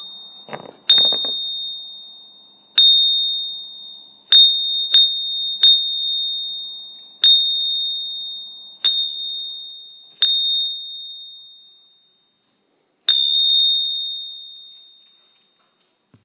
Ručné zvonček liatinový 21,5 cm
Materiál: liatina
rucni-zvonek-litinovy.m4a